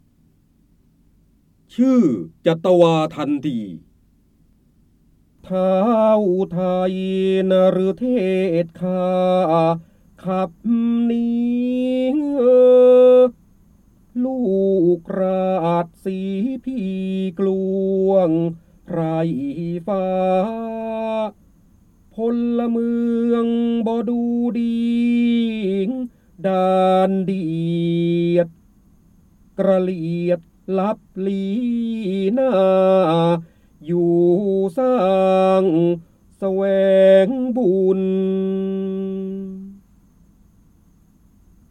เสียงบรรยายจากหนังสือ จินดามณี (พระโหราธิบดี) ชื่อจัตวาทัณฑี
คำสำคัญ : ร้อยกรอง, พระเจ้าบรมโกศ, พระโหราธิบดี, ร้อยแก้ว, จินดามณี, การอ่านออกเสียง